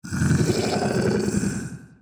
evil-deer-v2.ogg